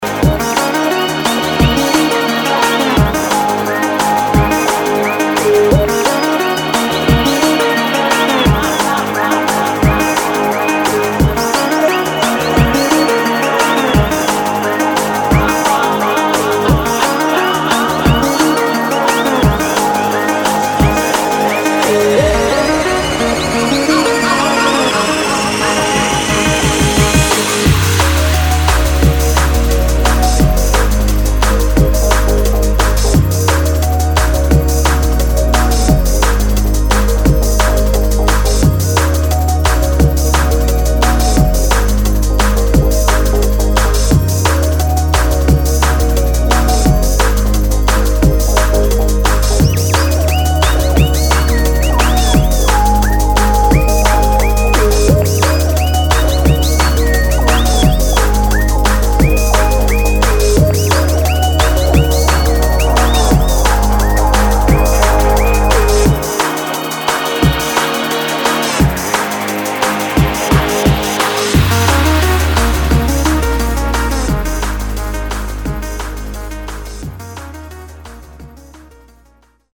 [ DRUM'N'BASS | JUNGLE ]